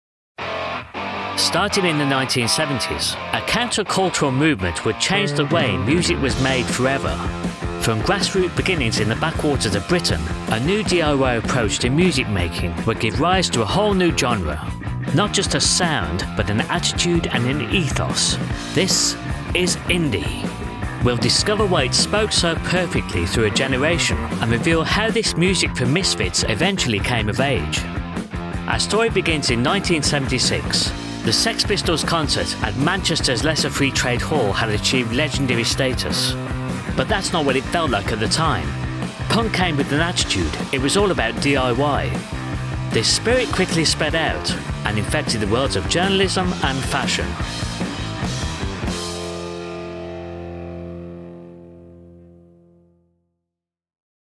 A dynamic British male voice. Positive, friendly and sincere with subtle Midlands tones.
Documentary
1106Doc-Music_For_Misfits.mp3